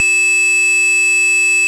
loud, piercing tone. Just imagine your ears ringing, only ten times louder.
texas-chainsaw-massacre-atari-2600-tone.wav